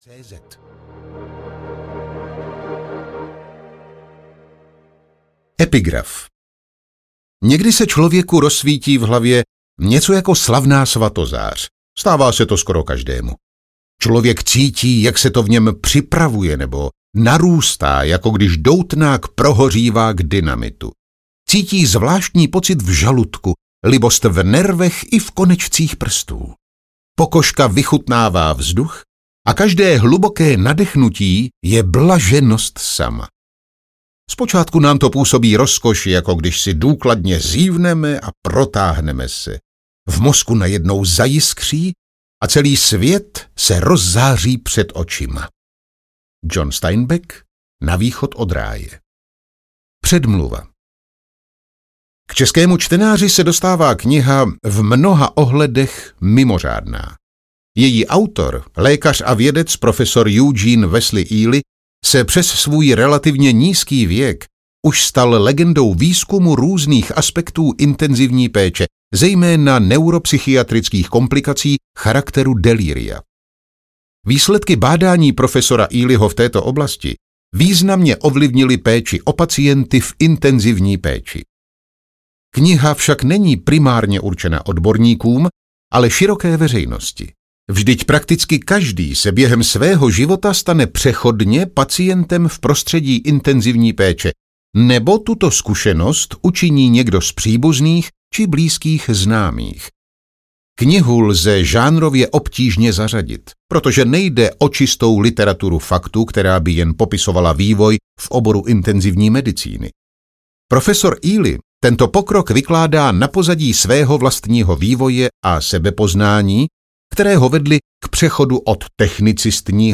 Každé hluboké nadechnutí audiokniha
Ukázka z knihy